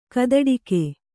♪ kadaḍike